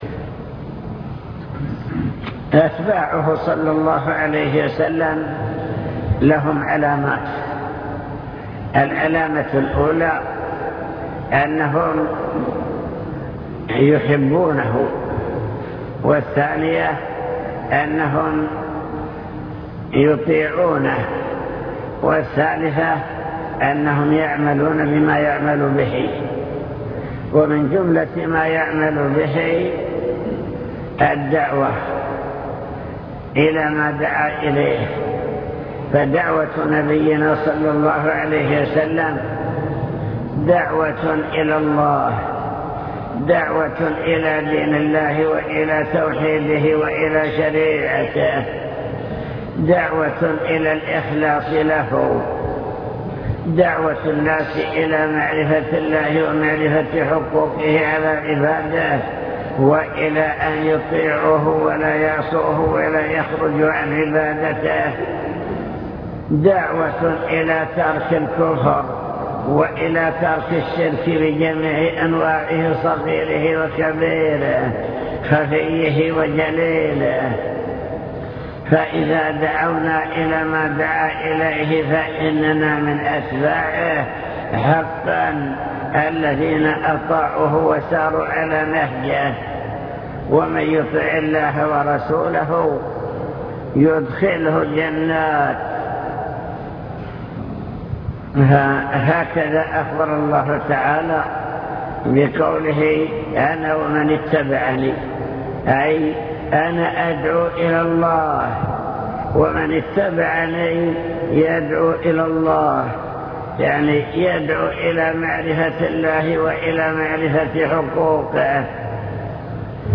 المكتبة الصوتية  تسجيلات - محاضرات ودروس  محاضرة في جامع حطين دعوة الأنبياء والرسل